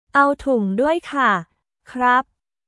アオ トゥン ドゥアイ カ／クラップ